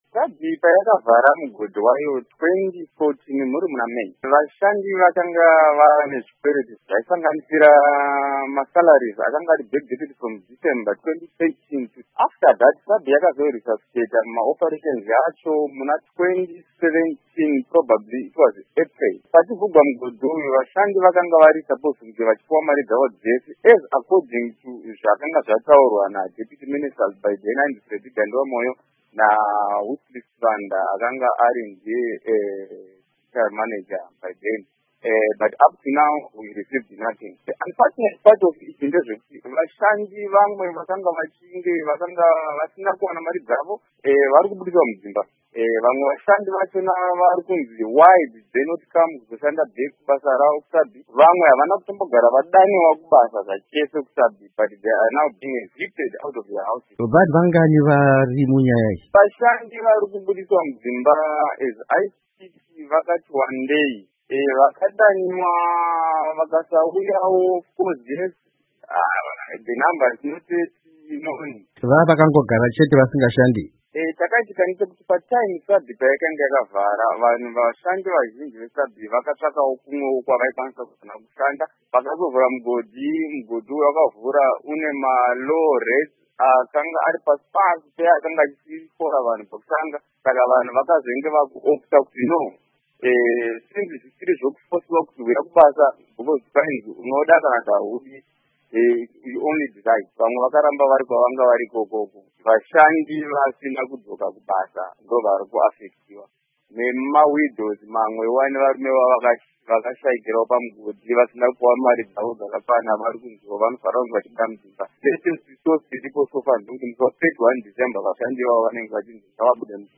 Hurukuro neMushandi weSabi Mine